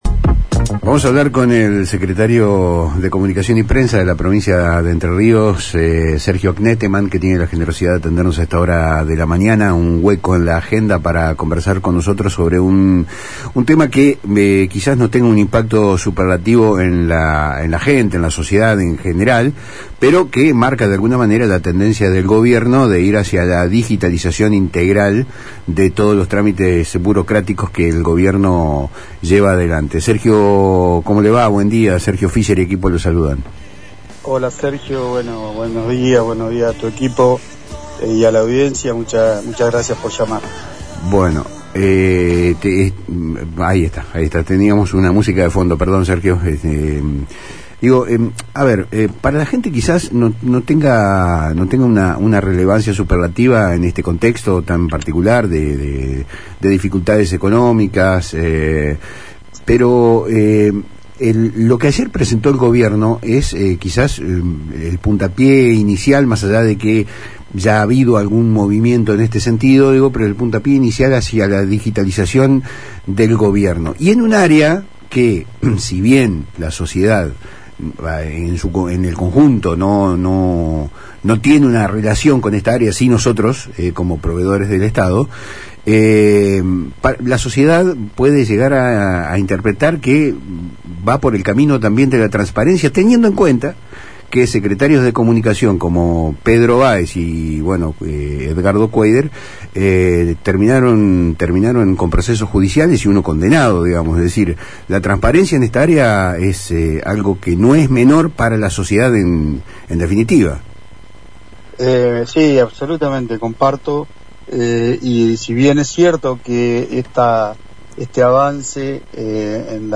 El Secretario de Comunicación y Prensa, Sergio Kneeteman, conversó con Palabras Cruzadas por FM Litoral sobre los detalles de este plan, destacando el rol pionero de su secretaría en esta transformación.